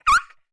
Index of /App/sound/monster/misterious_diseased_dog
damage_1.wav